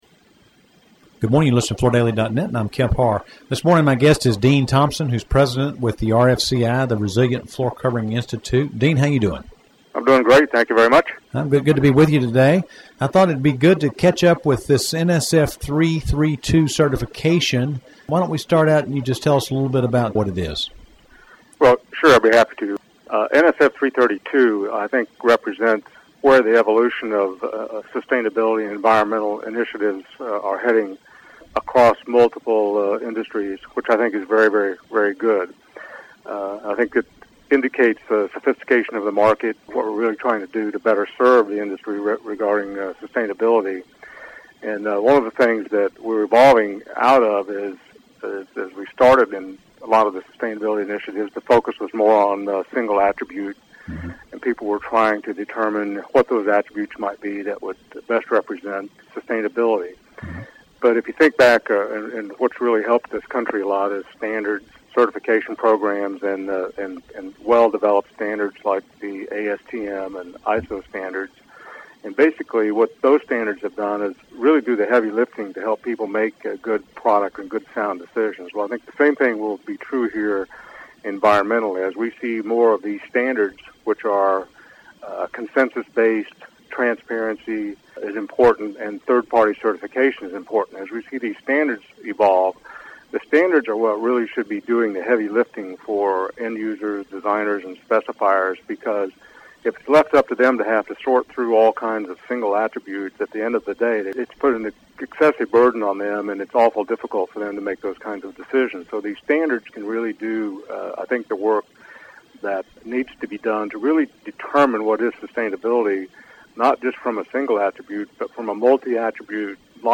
Listen to the interview to hear what products this standard covers, what attributes it evaluates, how many levels of compliance there are and what individual producers are doing to get certified.